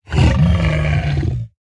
1.21.5 / assets / minecraft / sounds / mob / zoglin / angry3.ogg
angry3.ogg